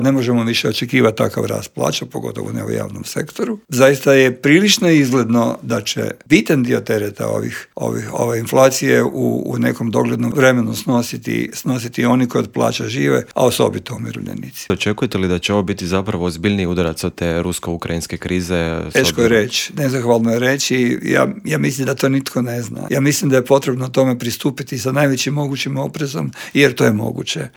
Saborski zastupnik iz redova platforme Možemo! Damir Bakić u Intervjuu Media servisa poručio je da će se povećanje cijena goriva preliti i na druga poskupljenja: "Teret ove krize podnijet će građani i umirovljenici".